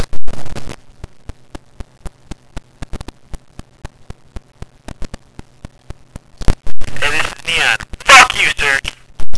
different profanity-filled sound files installed by rival hackers.